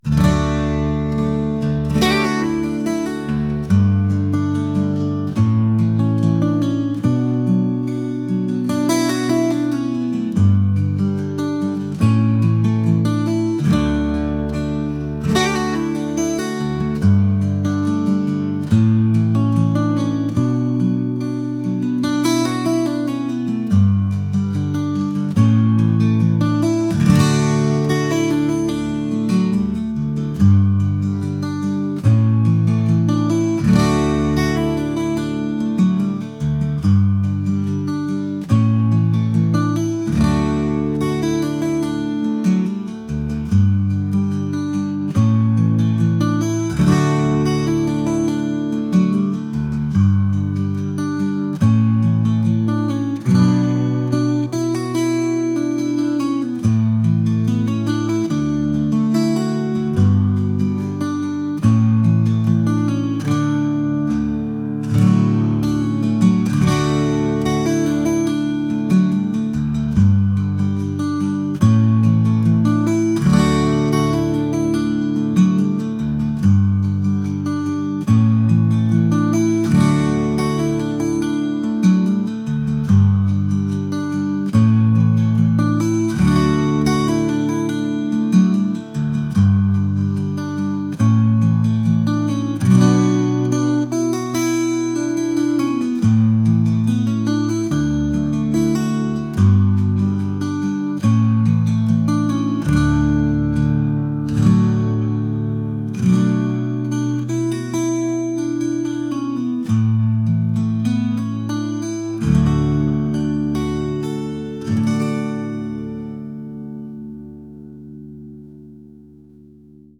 acoustic | folk | ambient